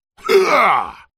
Darius.spellcasteffort3